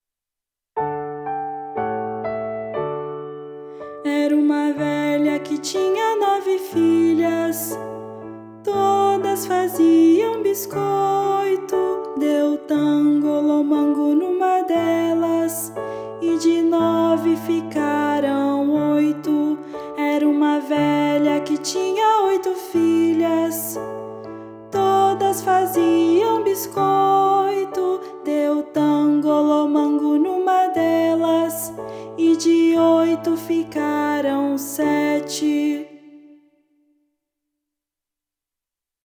Voz Guia 2